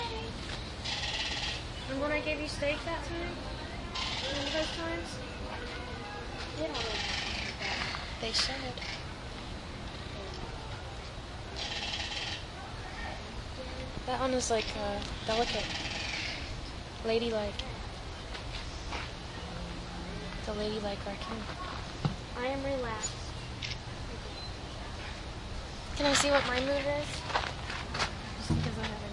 野生动物 " 狐狸叫声
描述：狐狸在远处（可能100米远）咆哮，凌晨1点左右在萨里山（英国）。
标签： 树皮 晚上 狐狸 农村 性质 现场记录 野生动物
声道立体声